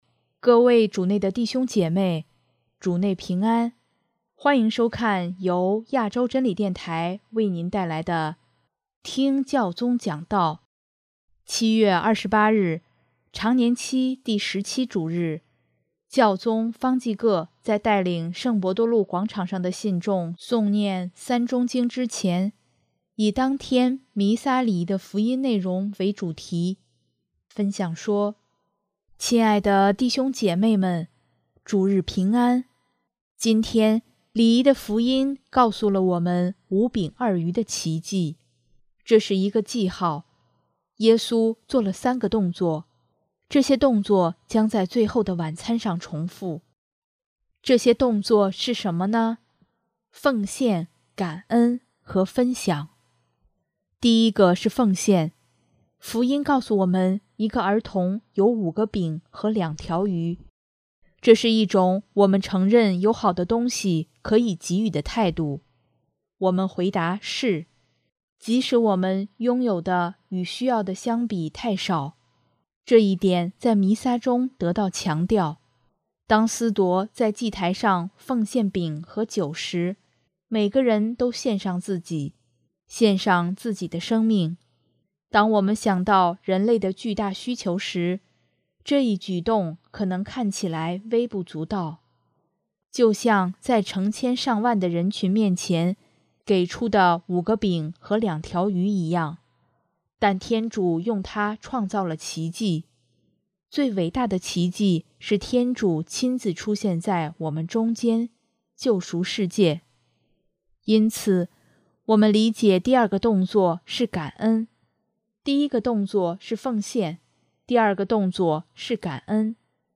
首页 / 新闻/ 听教宗讲道
7月28日，常年期第十七主日，教宗方济各在带领圣伯多禄广场上的信众诵念《三钟经》之前，以当天弥撒礼仪的福音内容为主题，分享说：